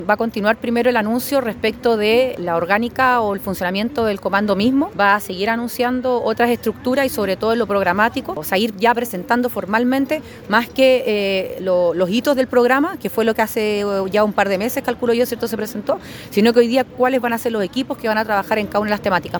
En la actividad de hoy se encontraba la senadora Paulina Núñez, una de las voceras de la candidata de Chile Vamos, quien aseguró que en las próximas semanas se van a anunciar las personas que trabajarán en cada área programática de la campaña.